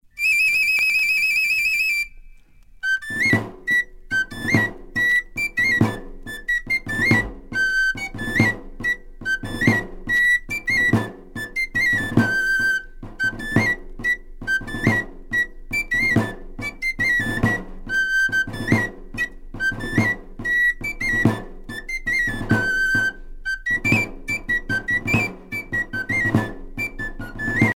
Mazurka (Landes girondines)
danse : mazurka
Pièce musicale éditée